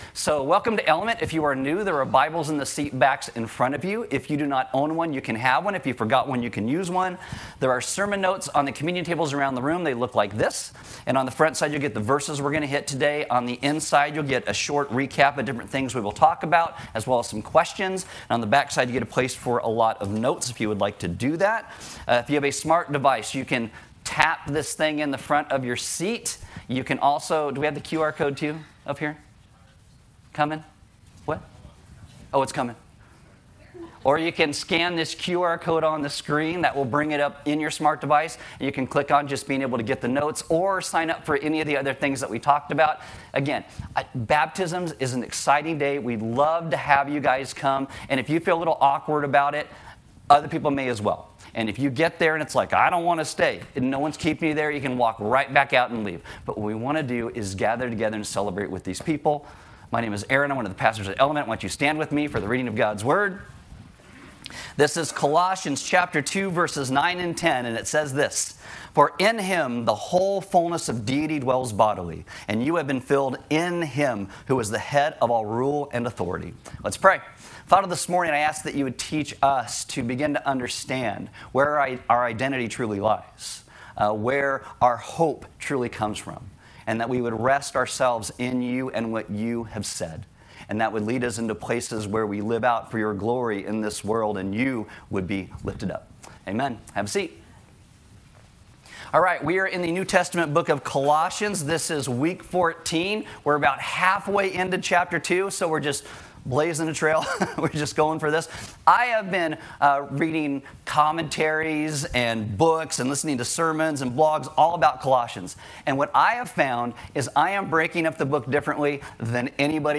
NOTE: We are still working on importing our vast sermon library.